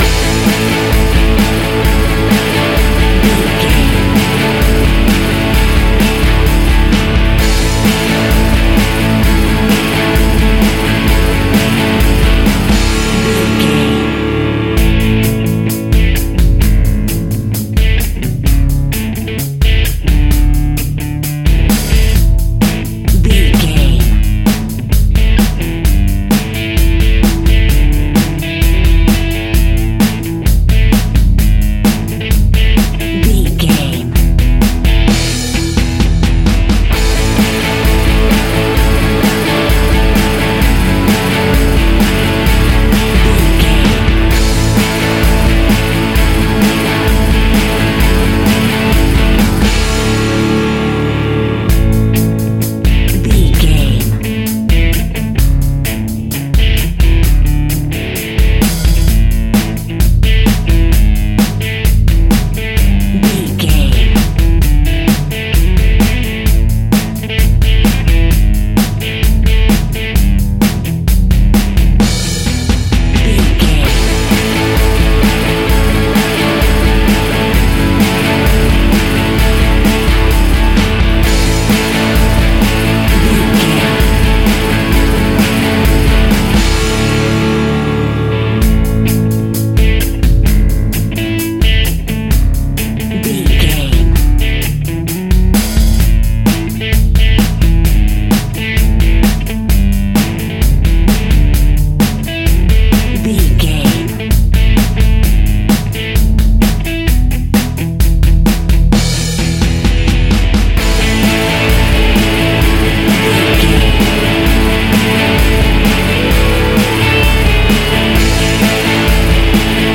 Upbeat Pop Rock Cue.
Ionian/Major
energetic
uplifting
electric guitar
bass guitar
drums
electric organ